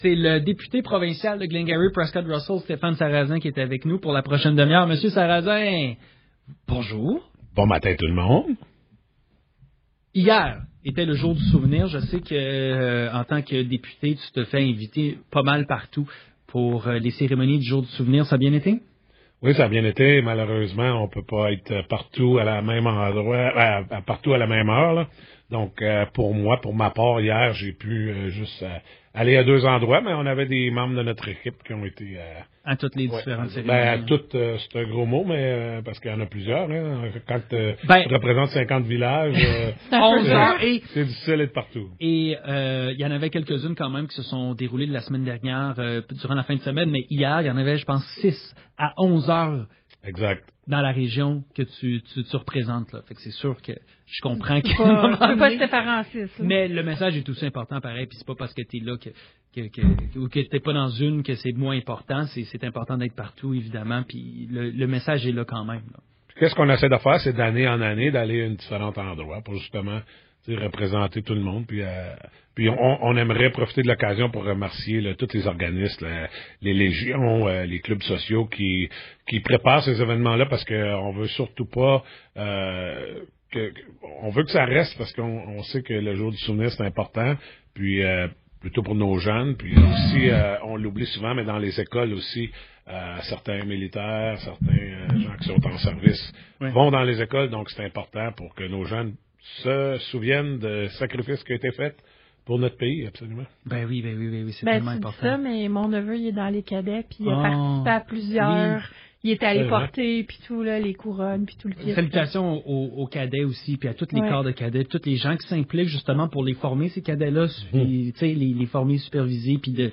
Le député provincial de Glengarry–Prescott–Russell, Stéphane Sarrazin, est venu passer une heure avec nous en studio.